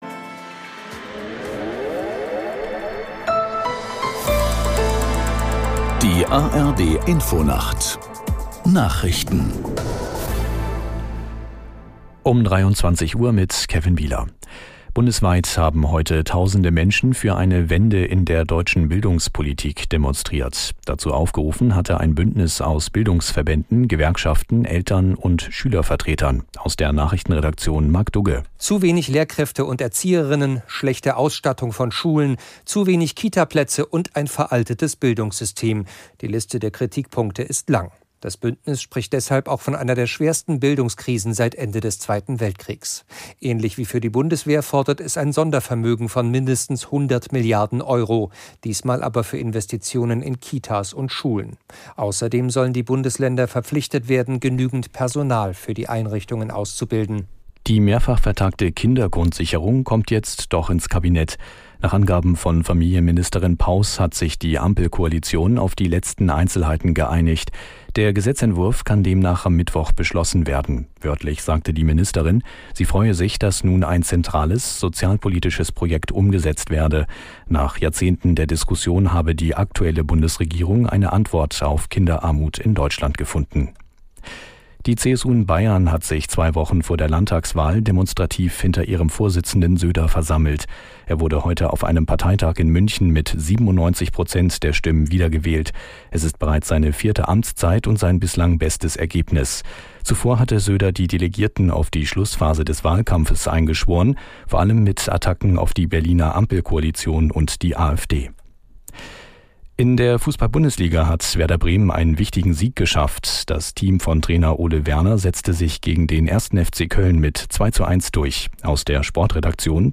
Nachrichten.